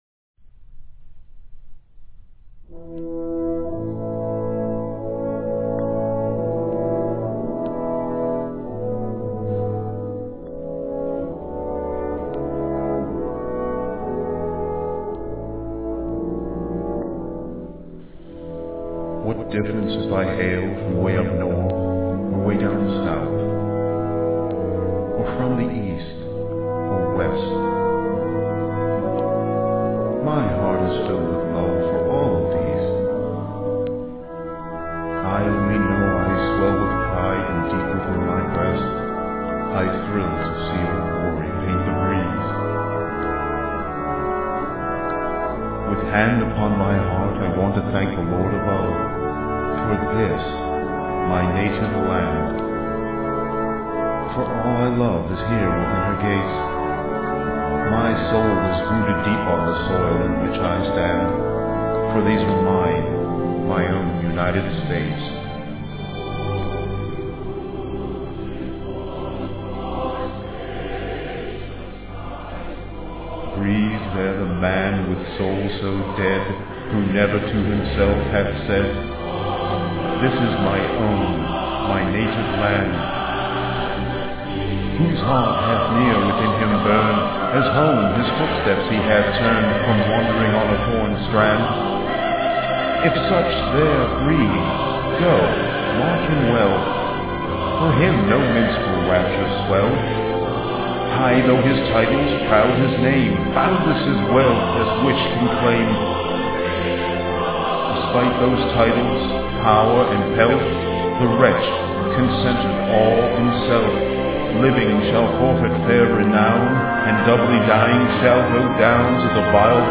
Being compressed, they sound "tinny" but the original MP3 files sound just fine and I've burnt them on to CD's to play in car stereos and such.